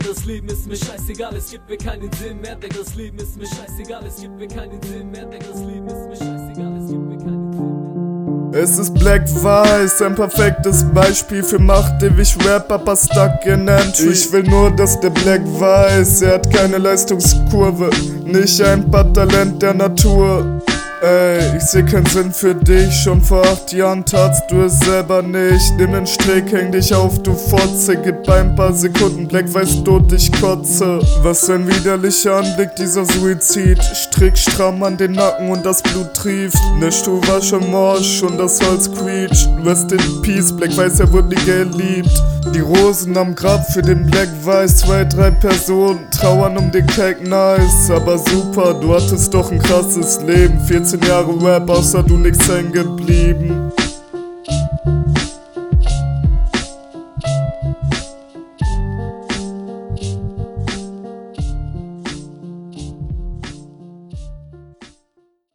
Finde dein style irgendwie gewöhnungsbedürftig, kommt bisschen gelangweilt und vorallem ziehst du die Silben so …
Sound ist okay, aber nichts überragendes.
Melancholischer Beat, interessant.